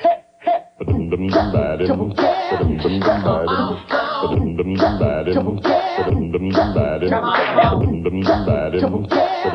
Found the missing segment of the opening riff.